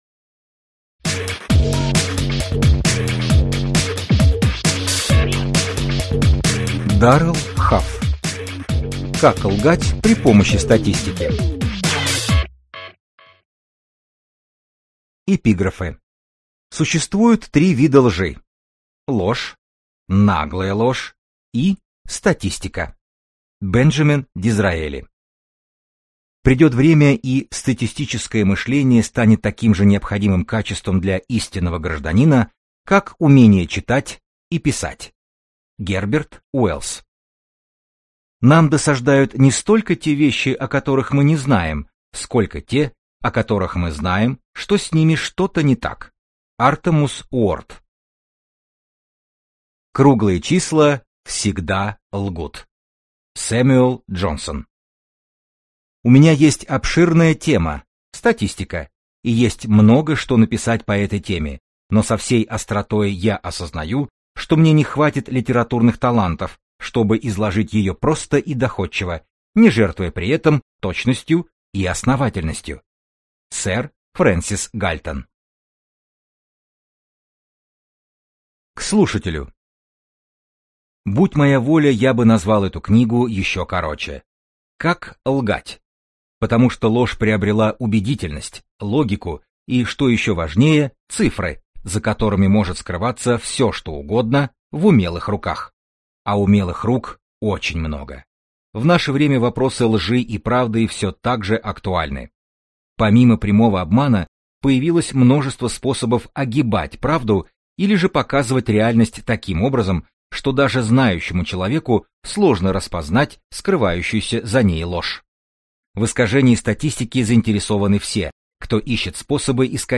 Аудиокнига Как лгать при помощи статистики | Библиотека аудиокниг